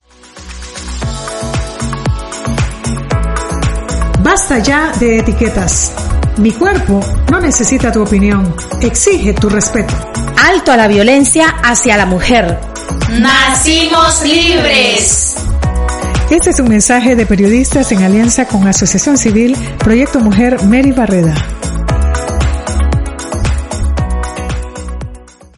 Les compartimos viñetas sobre la prevención de la violencia basada en género y la Explotación Sexual Comercial de niñas, niños y adolescentes elaboradas por mujeres periodistas y comunicadoras sociales integradas en los procesos de formación que desarrolla la organización.